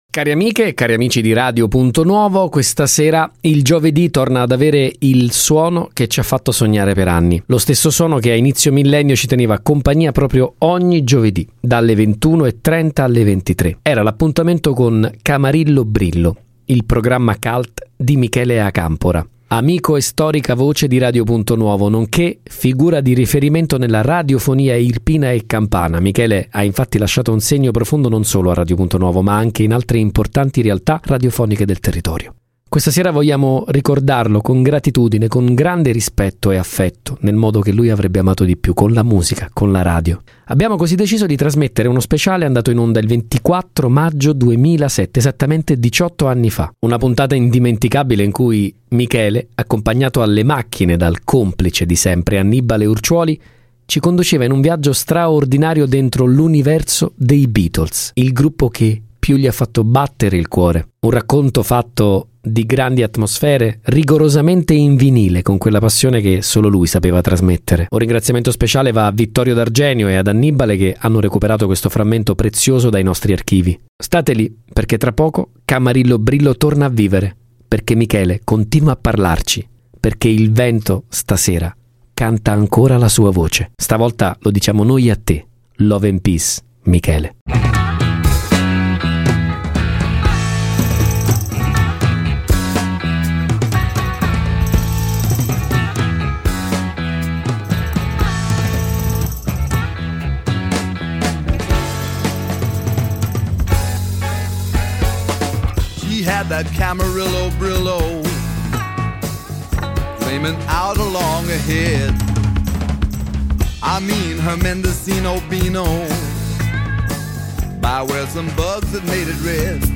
tutto rigorosamente in vinile!